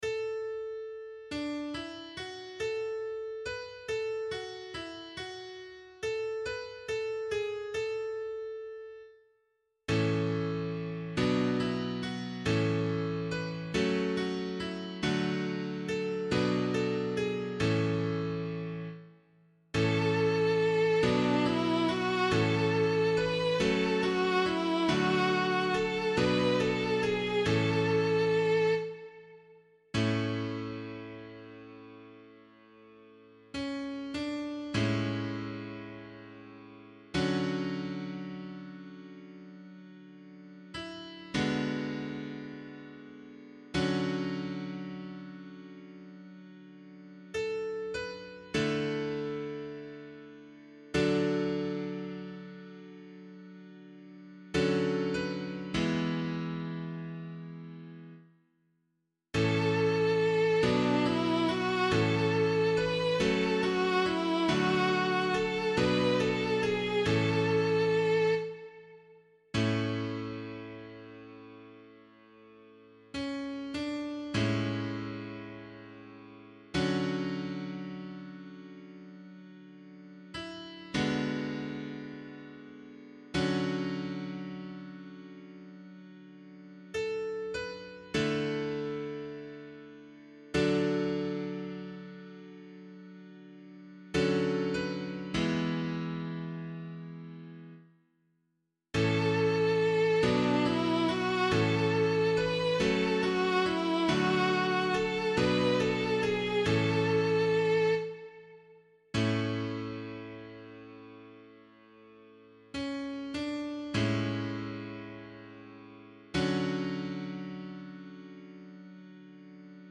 032 Pentecost Day Psalm [Abbey - LiturgyShare + Meinrad 8] - piano.mp3